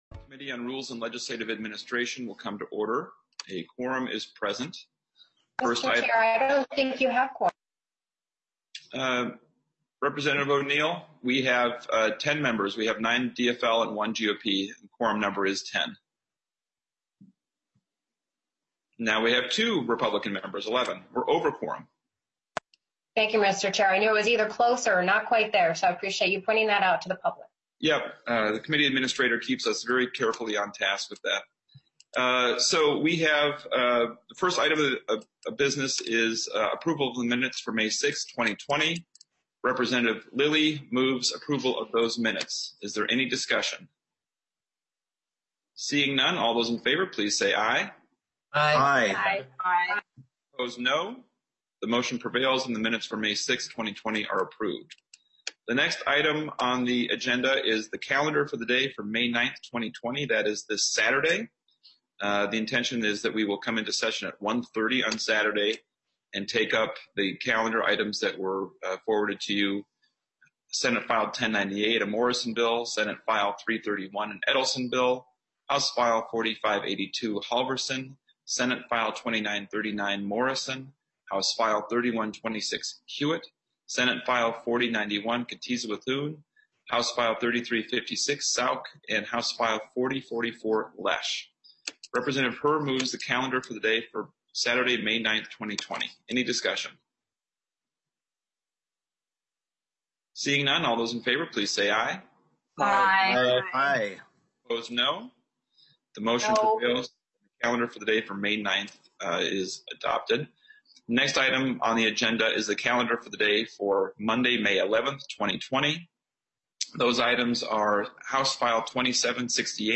Chair: Rep. Ryan Winkler